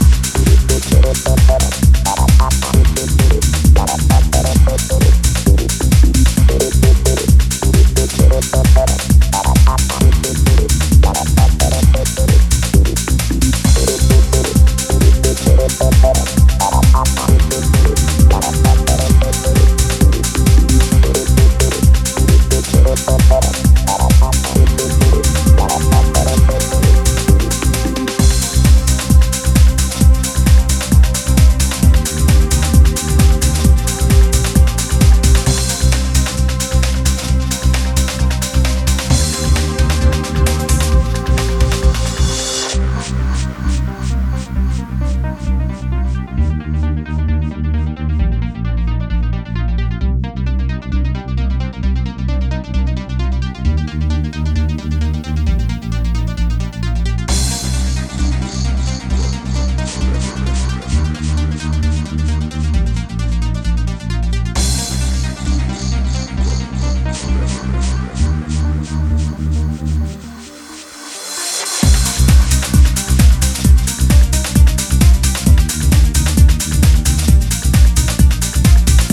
3 classy cuts navigating the progressive, trance milky way